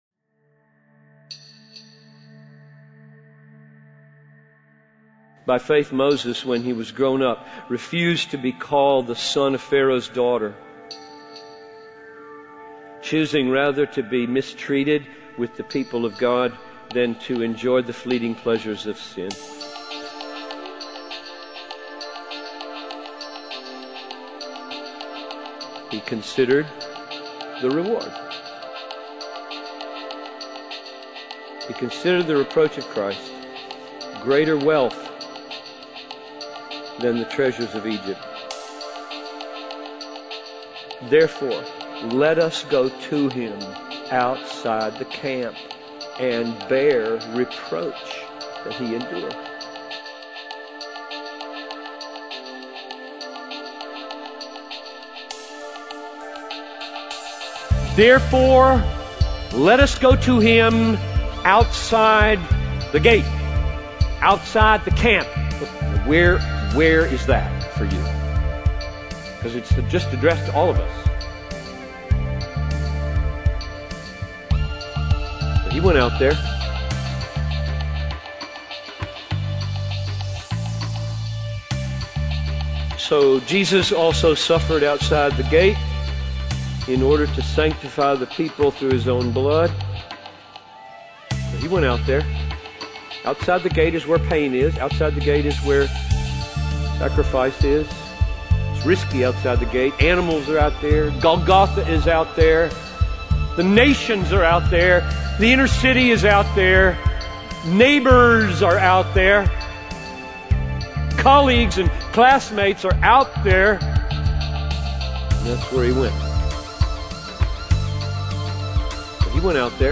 It contains excerpts from John Piper's sermon at T4G 2008 "How the Supremacy of Christ Creates Radical Christian Sacrifice."